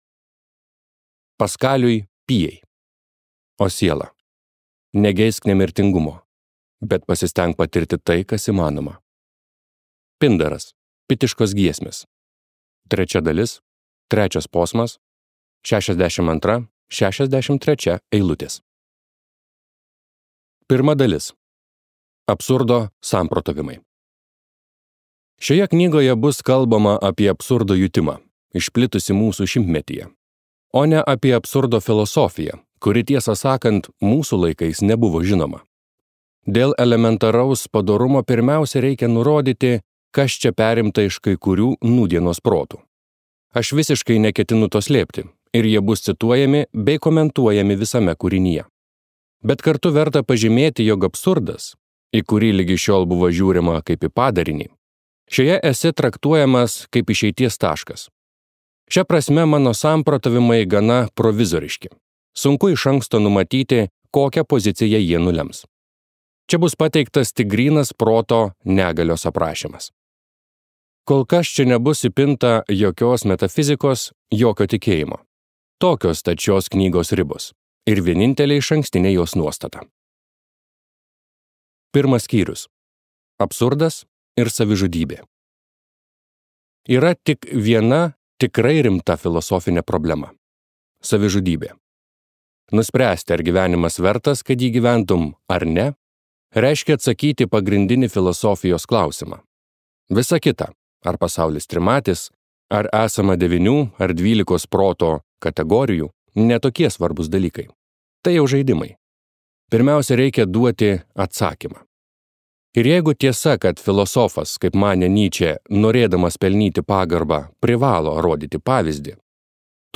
Sizifo mitas | Audioknygos | baltos lankos